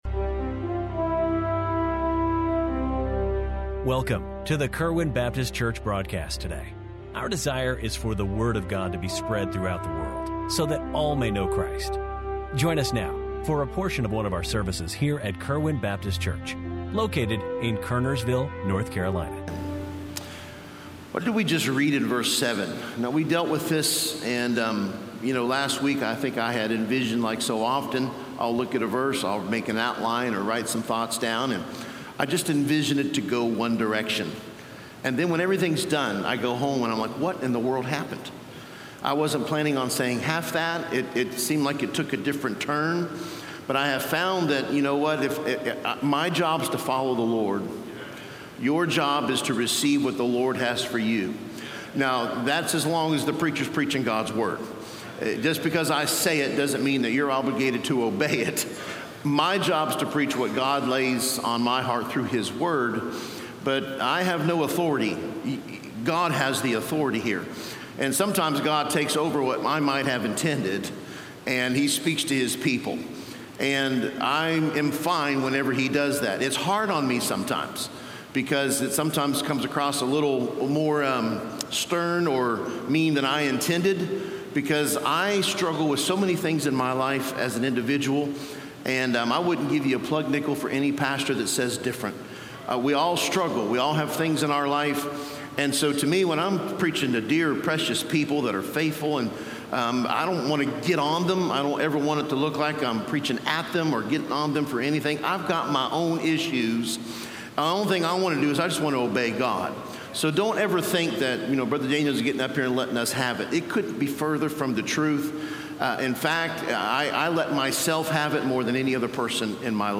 Kerwin Baptist Church Daily Sermon Broadcast